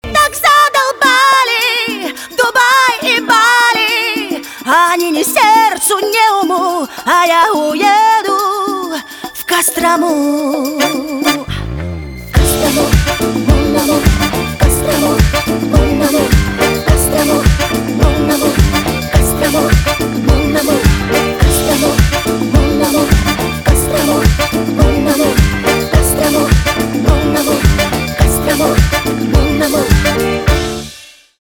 русский рок
гитара , барабаны , труба